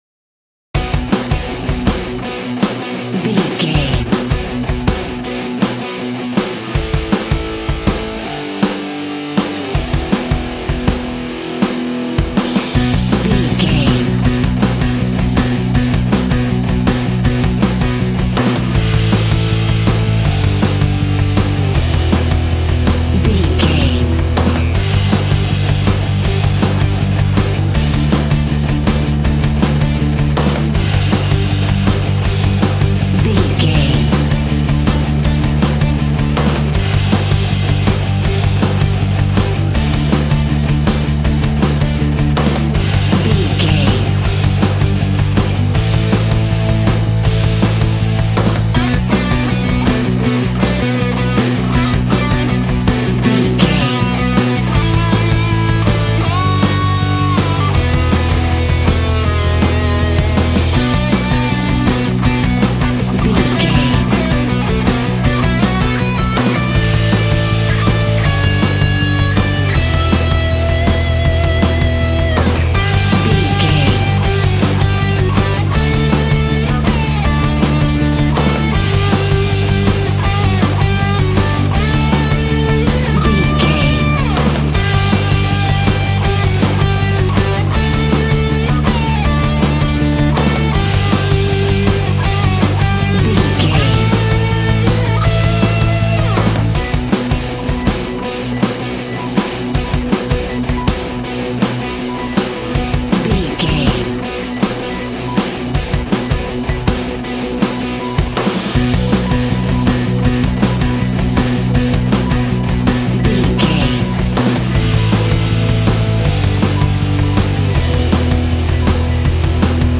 Ionian/Major
DOES THIS CLIP CONTAINS LYRICS OR HUMAN VOICE?
electric guitar
drums
bass guitar